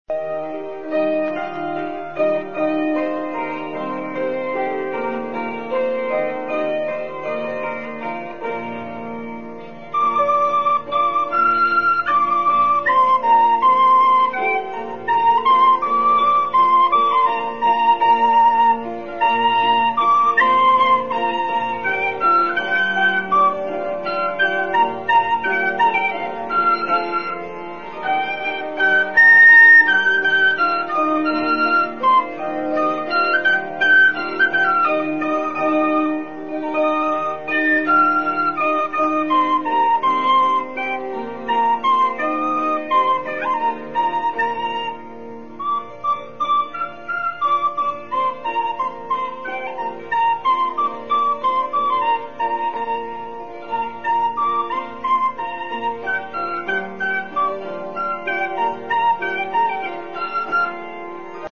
В фондах Марийского радио сохранились записи звучания народных инструментов
dudka.mp3